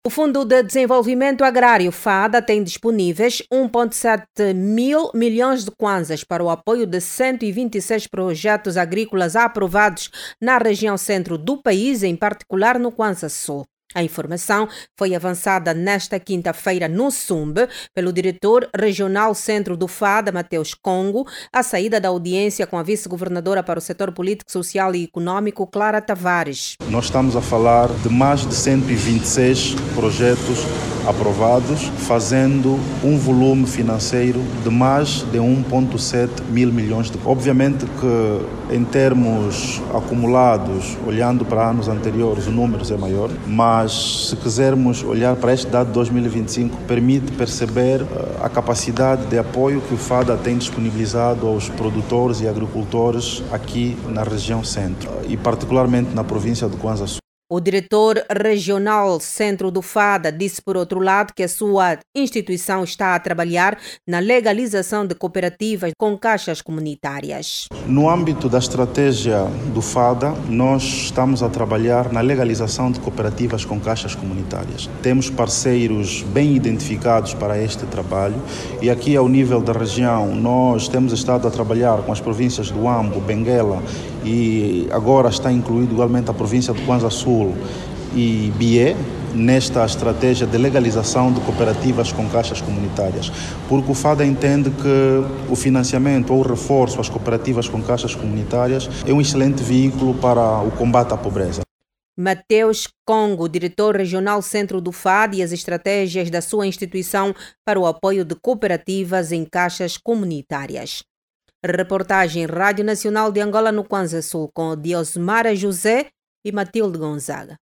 Os agricultores e produtores na região Centro Sul de Angola vão beneficiar do apoio do Fundo de Desenvolvimento Agrário, FADA. Na agenda de trabalho do FADA, está inscrita a legalização de cooperativas com caixas comunitárias nas Províncias do Huambo, Benguela, Bié e Cuanza-Sul. Ouça no áudio abaixo toda informação com a reportagem